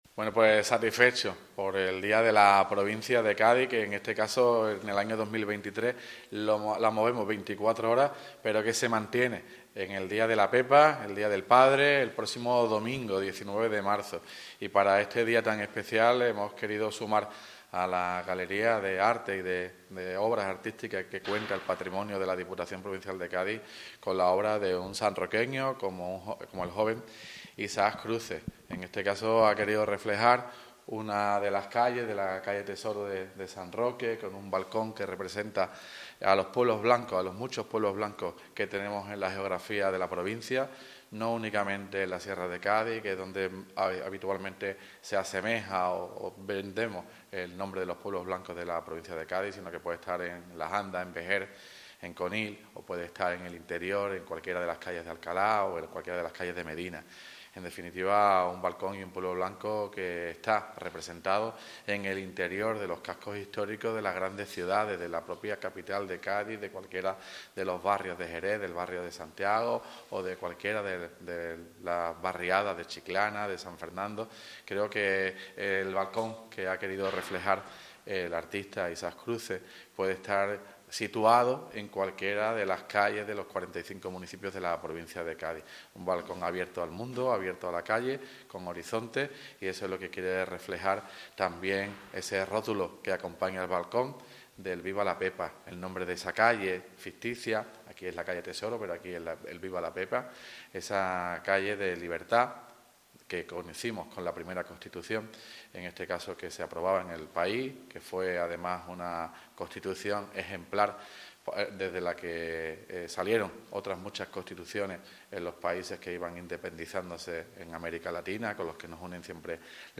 PRESENTACIÓN_PREMIOS_DIPUTACION_TOTAL_ALCALDE.mp3